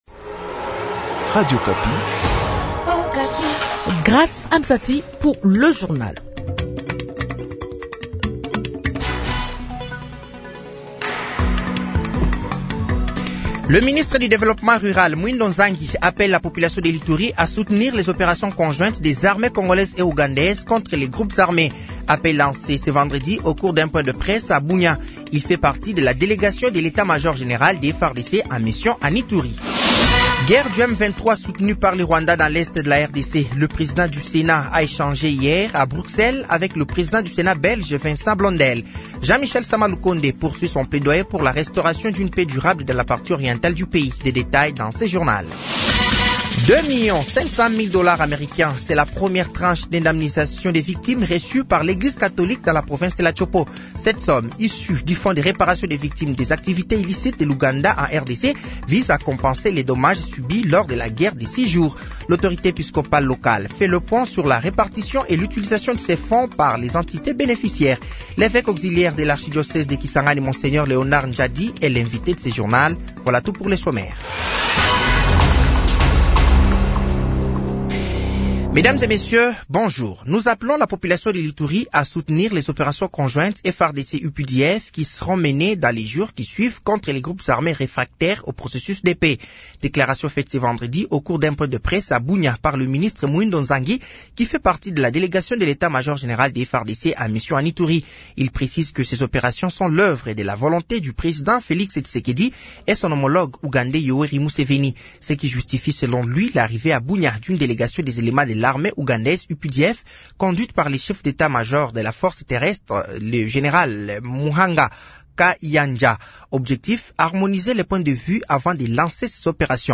Journal français de 15h de ce vendredi 28 mars 2025